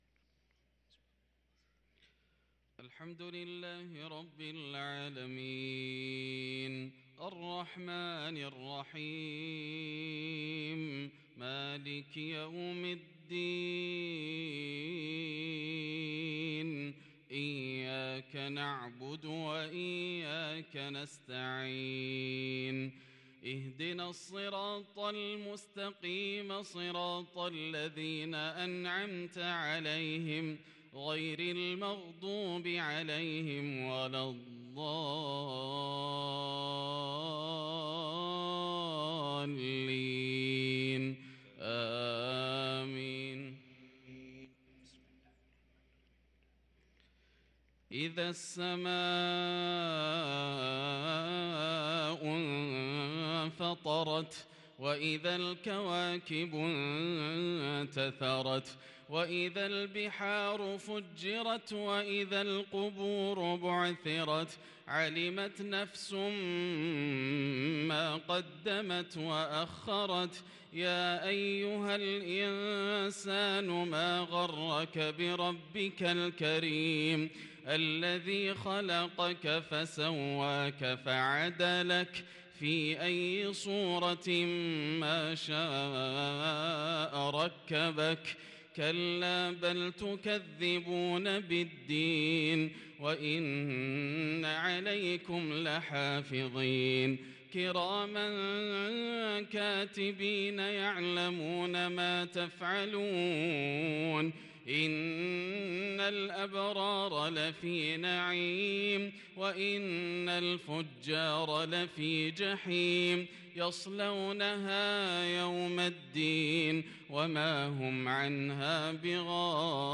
صلاة العشاء للقارئ ياسر الدوسري 1 صفر 1444 هـ